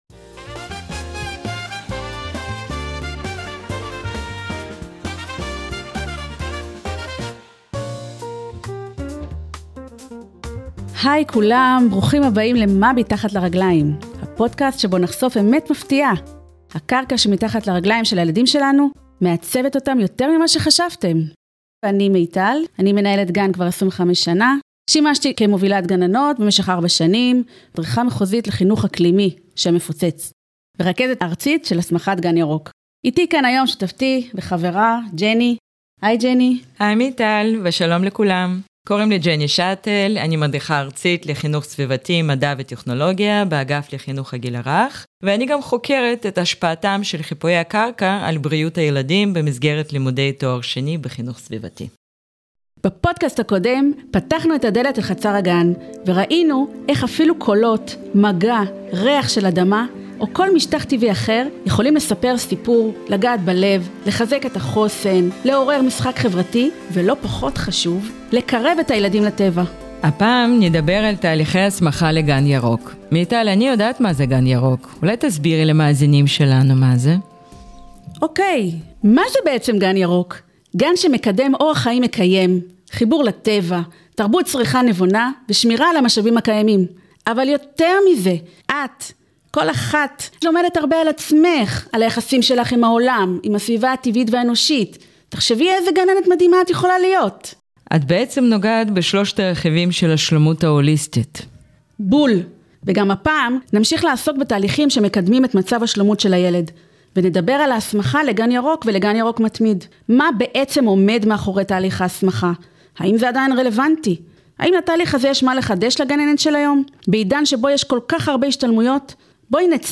הסכת על גן ילדים ירוק
בהסכת 'למה גן ירוק?' מדריכות לקיימות מנהלות דו שיח על הערך המוסף של תהליך ההסמכה לגן ירוק וגן ירוק מתמיד ומציגות דרכים לפיתוח כלים לקידום חינוך לשלומות במאה ה-21.